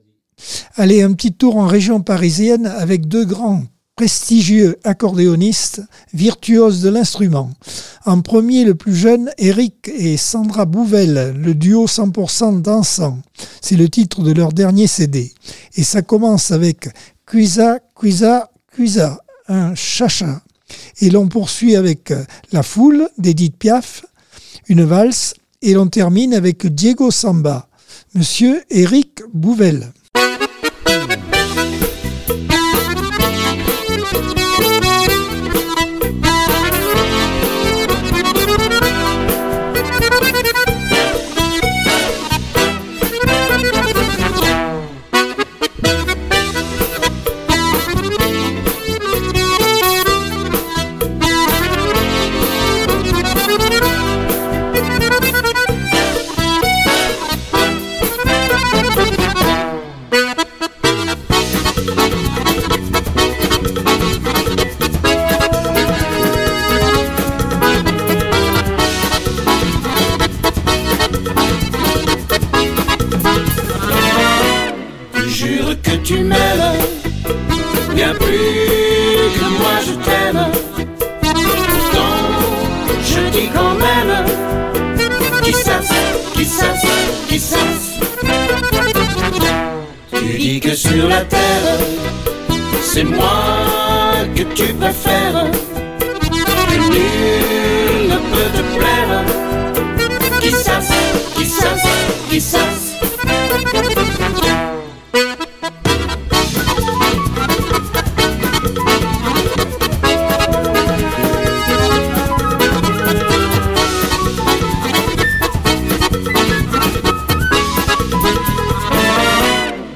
Accordeon 2024 sem 20 bloc 3 - Radio ACX